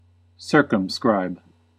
Ääntäminen
Synonyymit restrict Ääntäminen US UK : IPA : /ˈsɜː.kəm.skɹaɪb/ US : IPA : /ˈsɝ.kəm.skɹaɪb/ Haettu sana löytyi näillä lähdekielillä: englanti Määritelmät Verbit To draw a line around ; to encircle . To limit narrowly; to restrict .